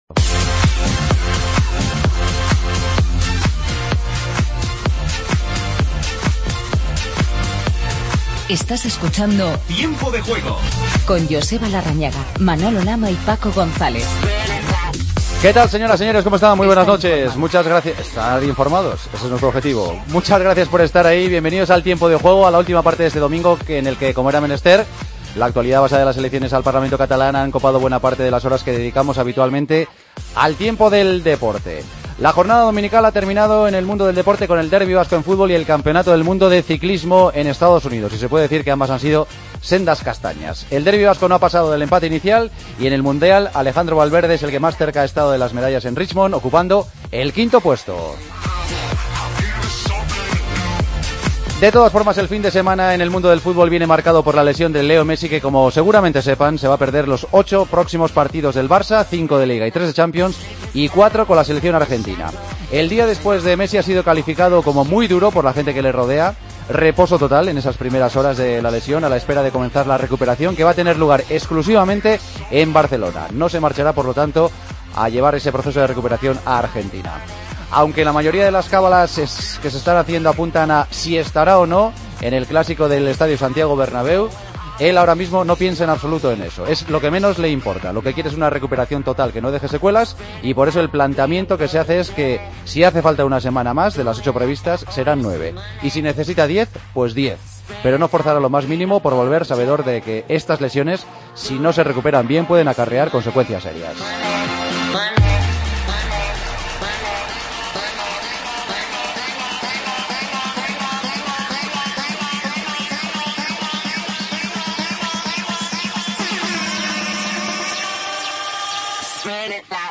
Redacción digital Madrid - Publicado el 28 sep 2015, 01:22 - Actualizado 13 mar 2023, 20:21 1 min lectura Descargar Facebook Twitter Whatsapp Telegram Enviar por email Copiar enlace Titulares del día. Resumimos el derbi vasco que acabó con 0-0 y escuchamos los sonidos del domingo. ¿Cómo ha sido el día después para Villarreal, Barcelona, Real Madrid y Atlético de Madrid?